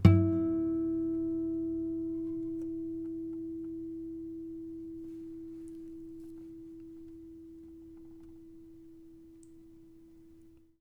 harmonic-04.wav